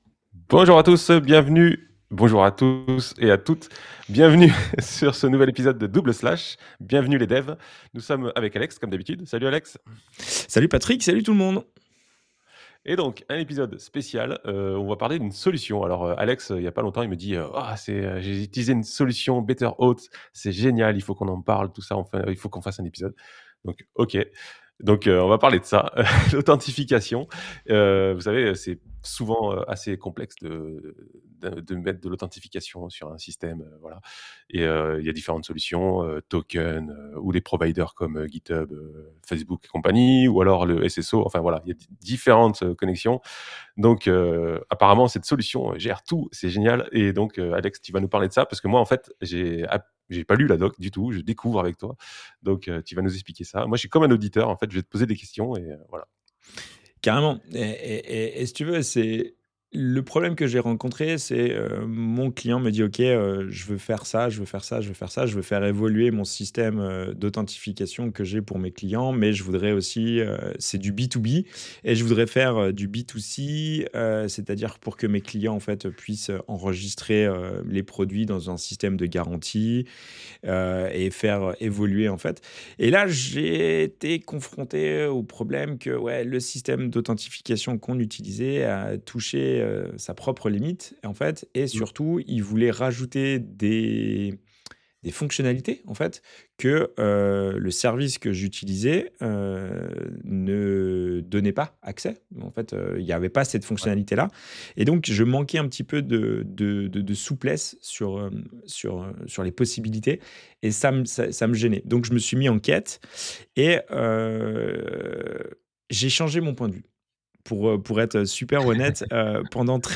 Double Slash, un podcast avec 2 animateurs qui se retrouvent pour discuter des outils et des techniques pour le développement moderne de site web et d’application web. Retrouvez-nous régulièrement pour parler de sujets variés tels que la JAMStack, l’accessibilité, l’écoconception, React JS, Vue JS et des retours d’expériences sur des implémentations.